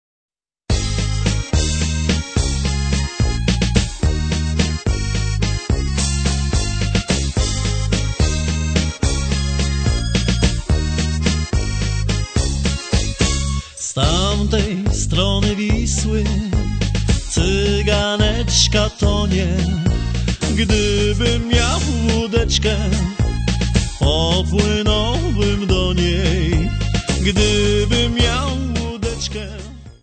3 CD set of Polish Folk Songs.